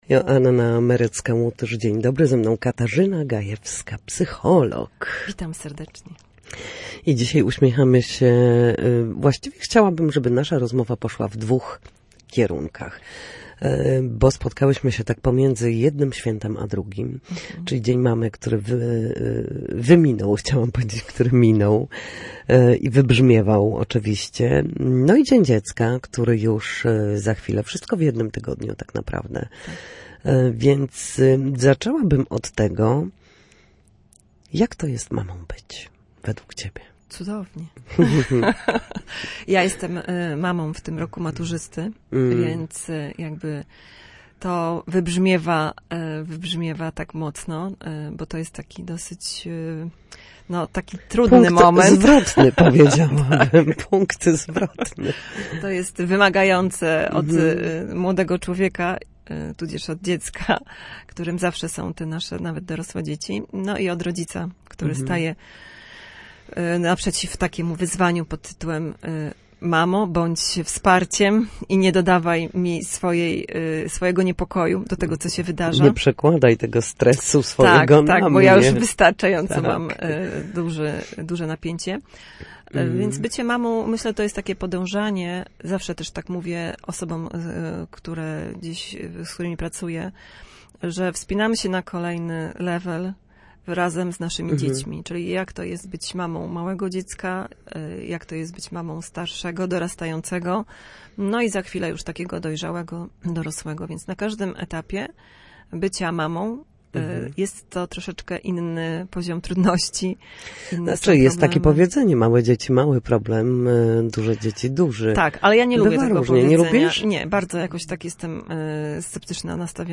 W każdą środę, w popołudniowym paśmie Studia Słupsk Radia Gdańsk, rozmawiamy o tym, jak wrócić do formy po chorobach i urazach.